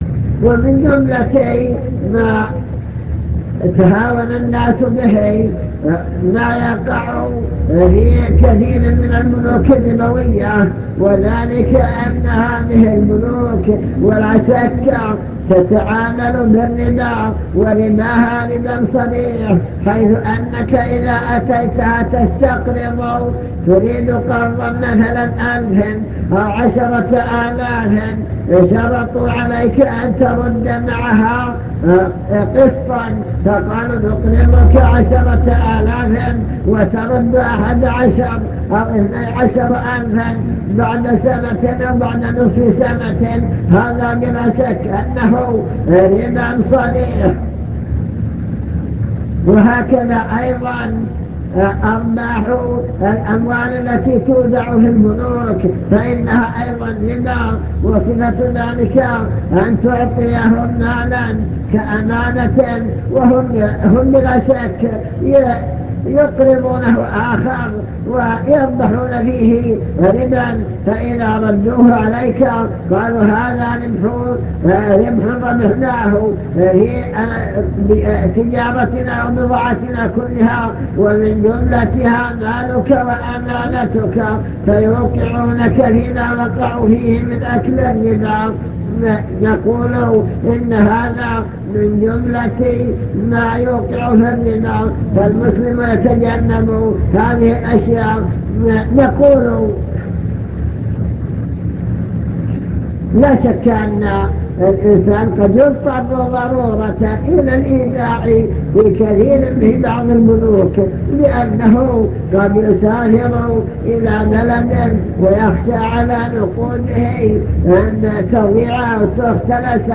المكتبة الصوتية  تسجيلات - محاضرات ودروس  الربا وما يتعلق به من أحكام الربا